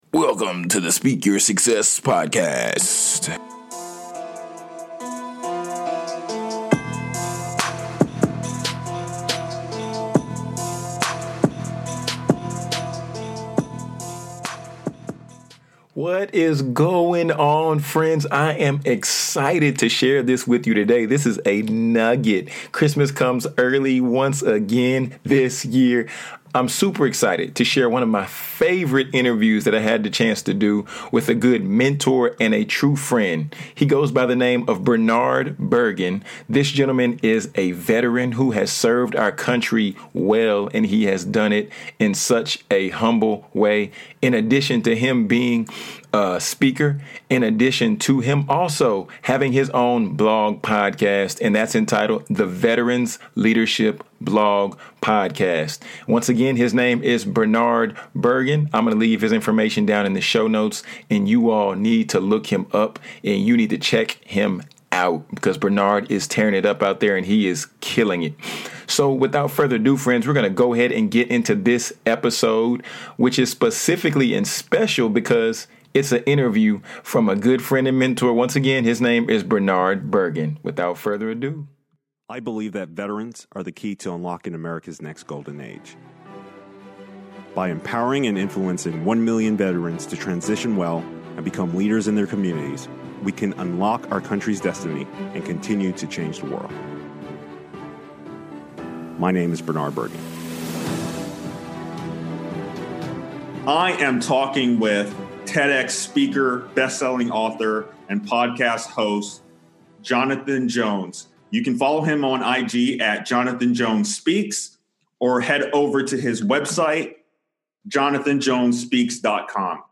He put me on the hot seat asking a barrage of questions.